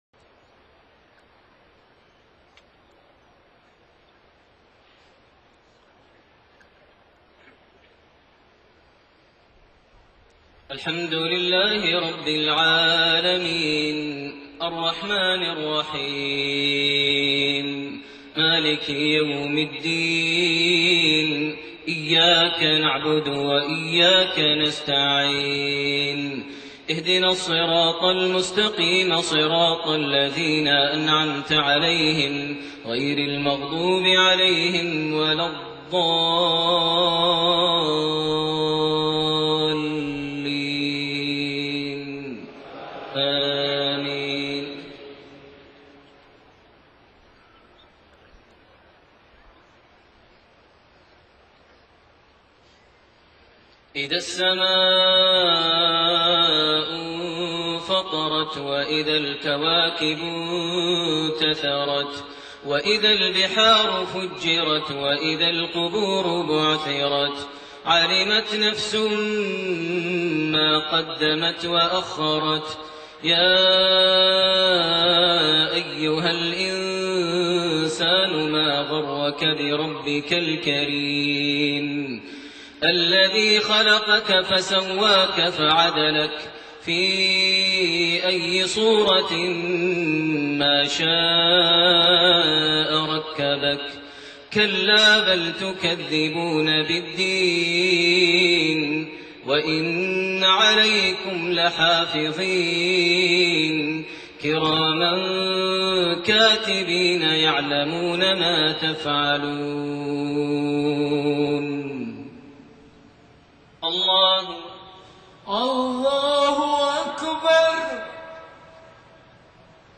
صلاة المغرب3-8-1428 سورة الانفطار > 1428 هـ > الفروض - تلاوات ماهر المعيقلي